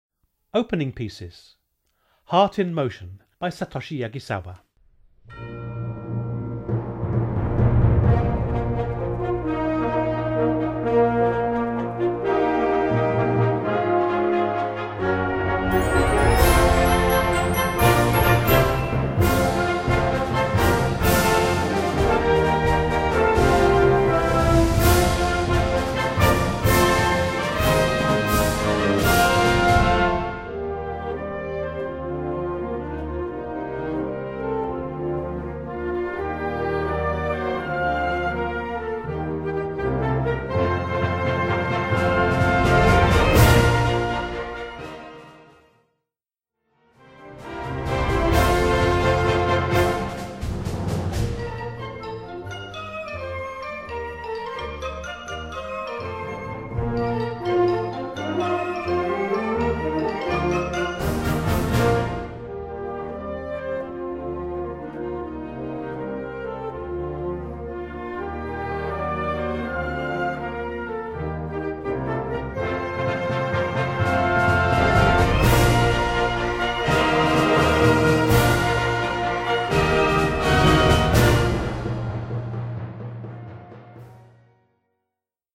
Gattung: Eröffnungswerk
Besetzung: Blasorchester